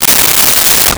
Paper Tear 09
Paper Tear 09.wav